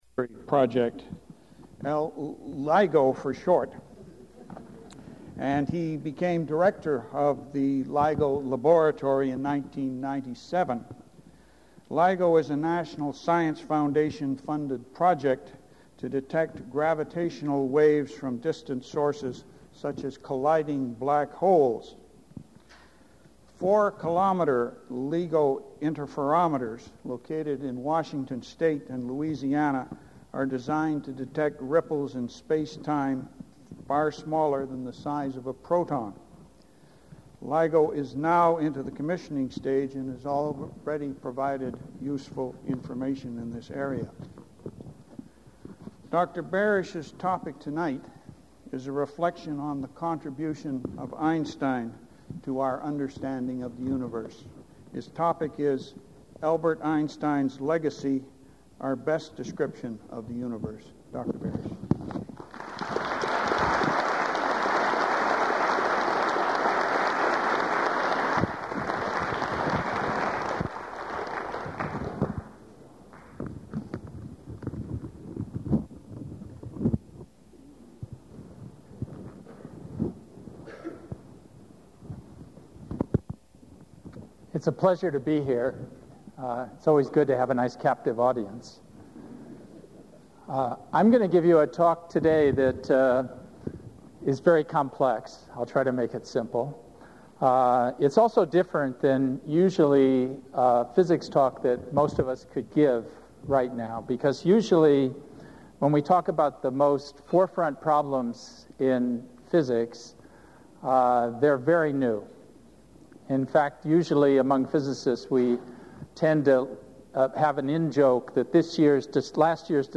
Item consists of a digitized copy of an audio recording of a TRIUMF Lecture delivered at the Vancouver Institute by Barry Barish on April 16, 2005.